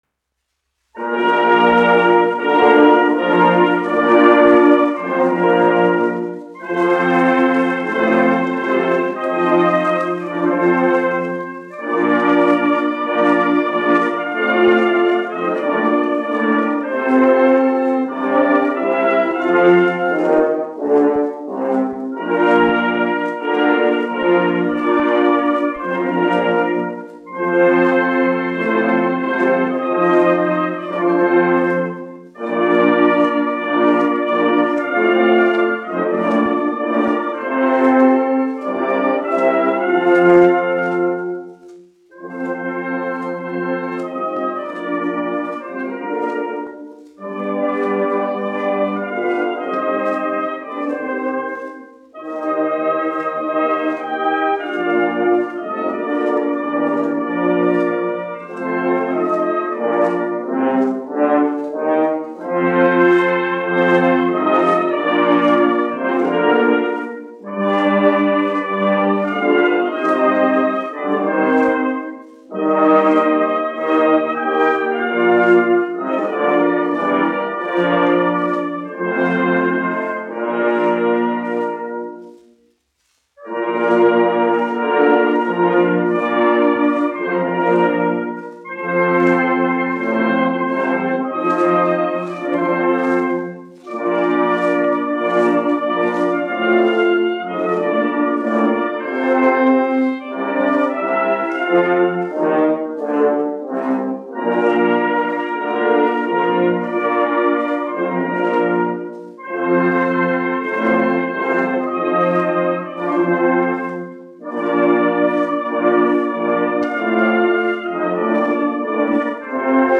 6. Rīgas kājnieku pulka orķestris, izpildītājs
1 skpl. : analogs, 78 apgr/min, mono ; 25 cm
Nacionālās dziesmas un himnas
Pūtēju orķestra mūzika, aranžējumi
Skaņuplate